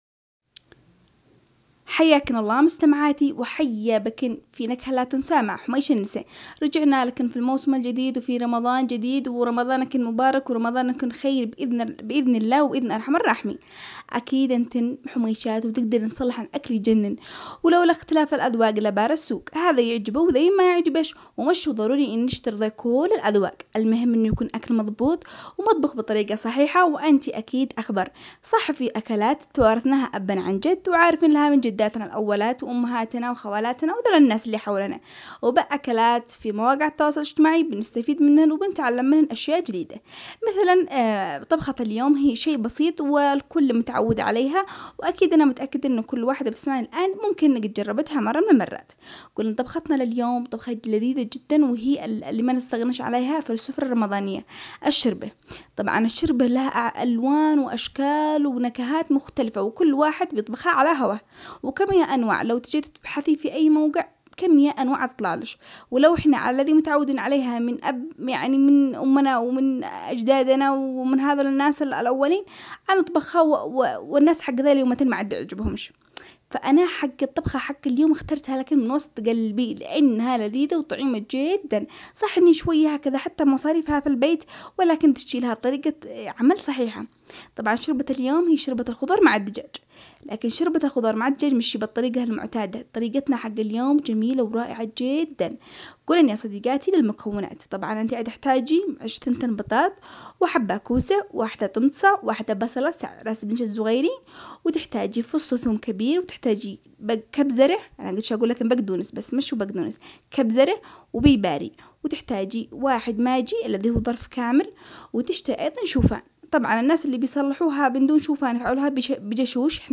نشرة اخبارية تهتم بأخبار المرأة اليمنية والفعاليات والانشطة التي تشارك فيها وتخصها